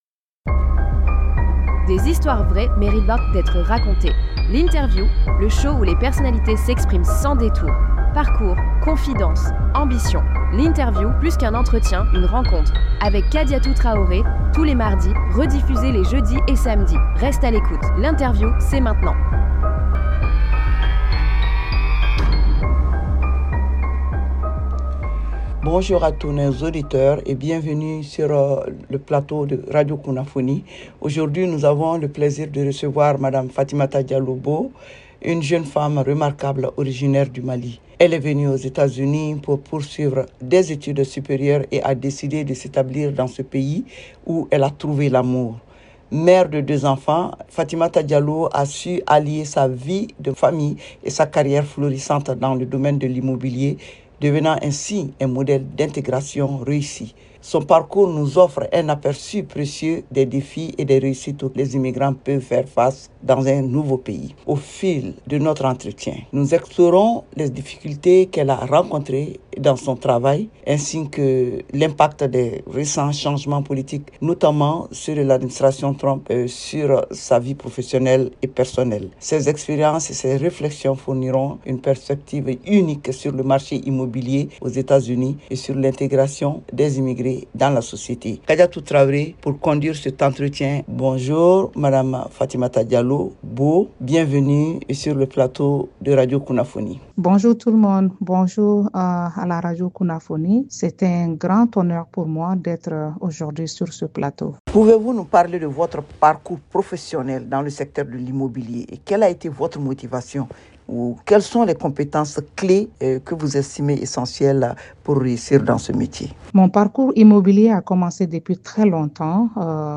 L'INTERVIEW
Des échanges francs, des réponses sans filtre.